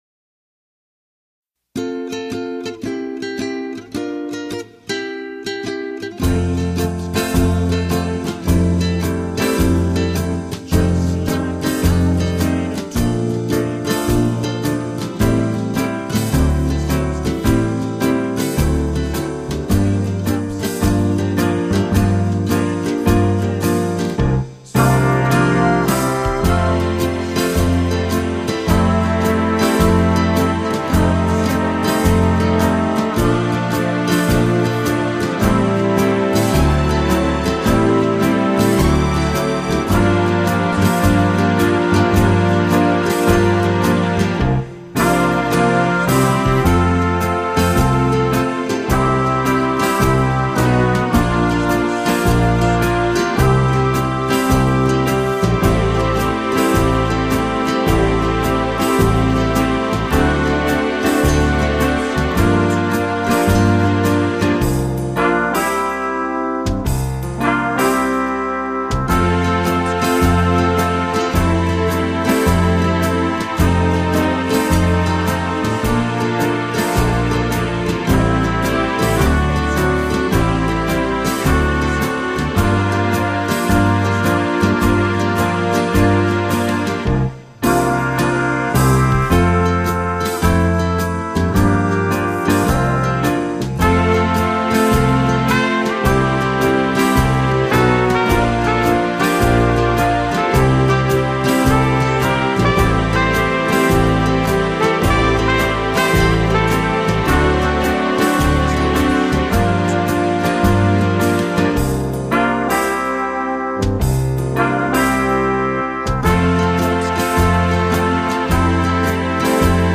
A political song parody…
It’s a catchy tune, so why not sing along!